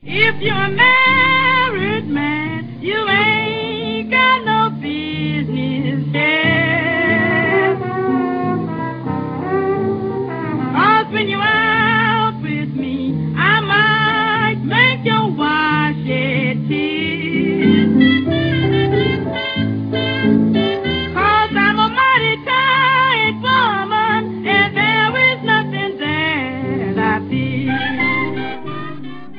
фортепиано, вокал
корнет
кларнет
тромбон
Блюзовая певица